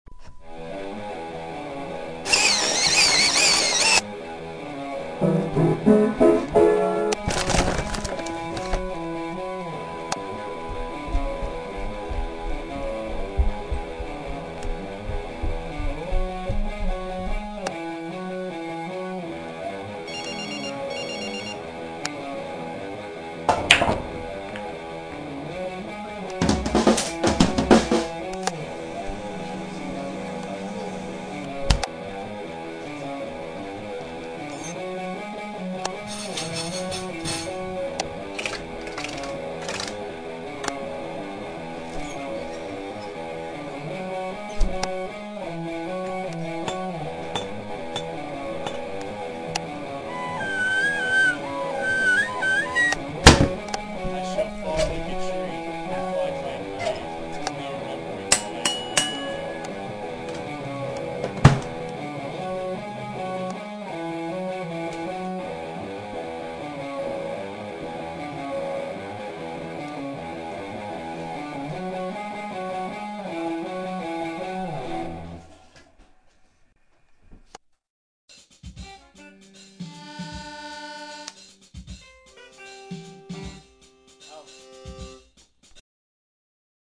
bluesy random-sound-fest